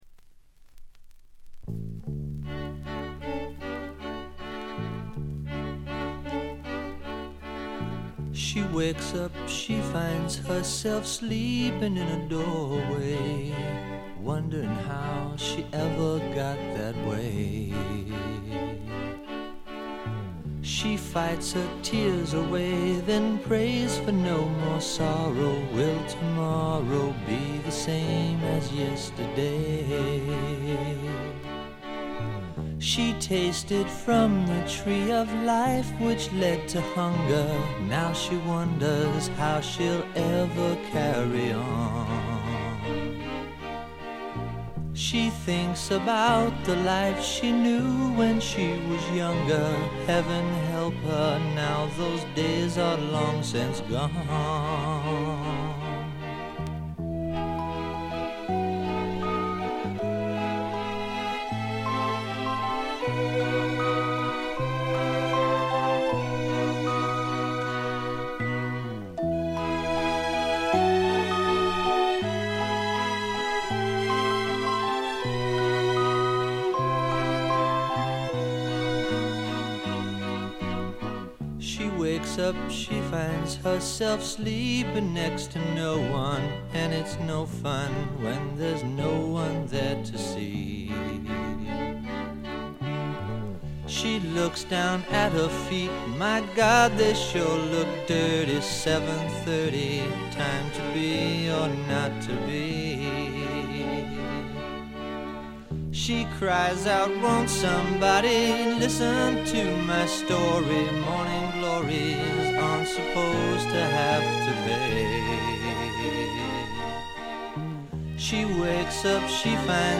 これ以外はわずかなノイズ感のみで良好に鑑賞できると思います。
試聴曲は現品からの取り込み音源です。
Recorded in Hollywood, California.